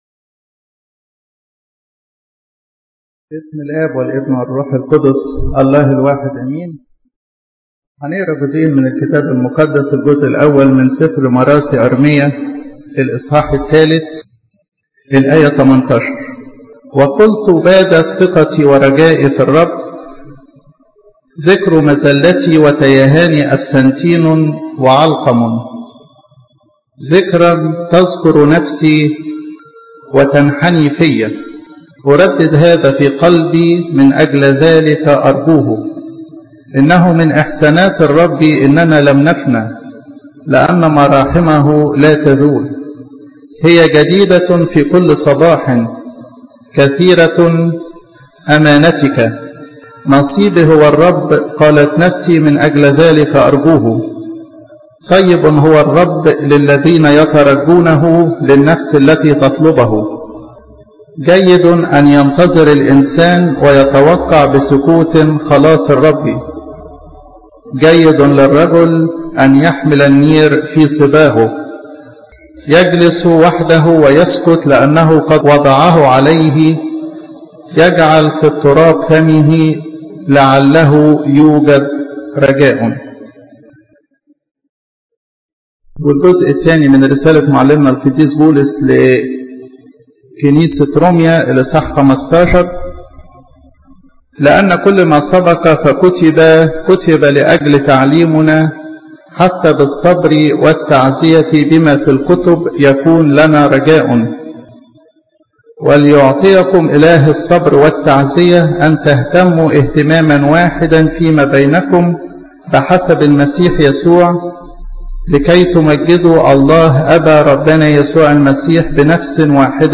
عظات روحية ج4